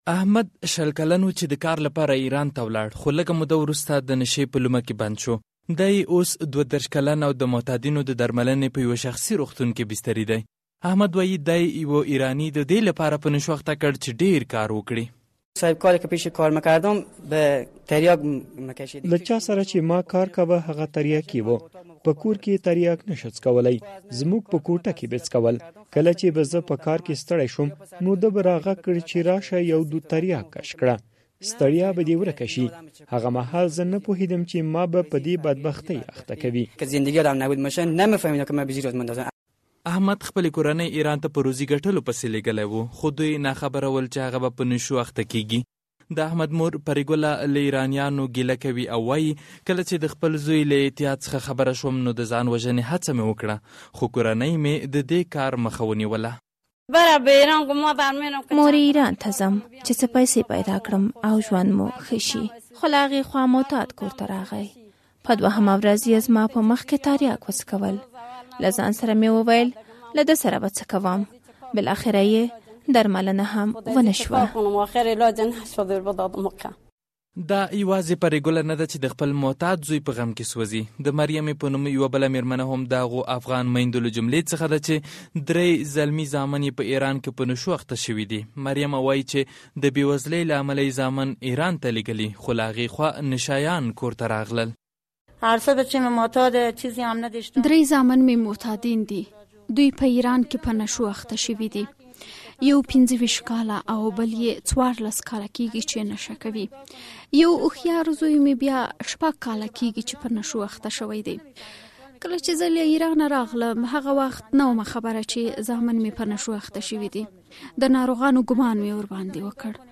هرات راپور